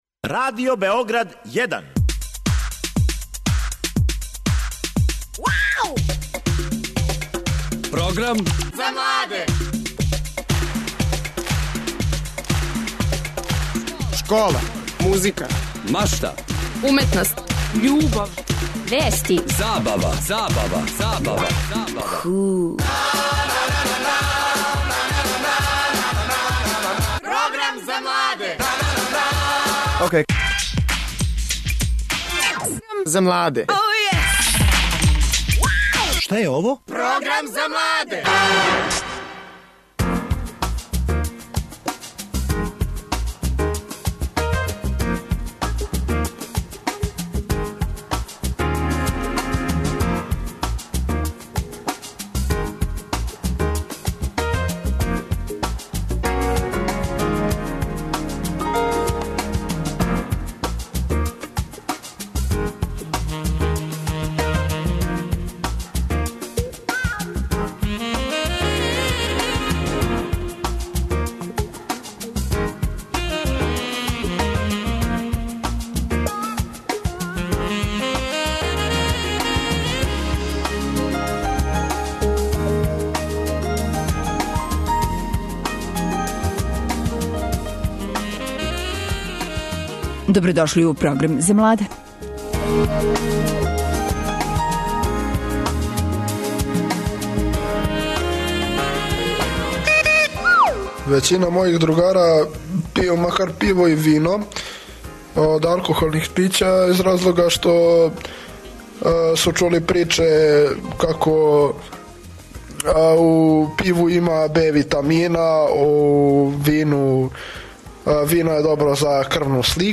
Како изгледа једна средњошколска екскурзија, рећи ће нам туристички водичи.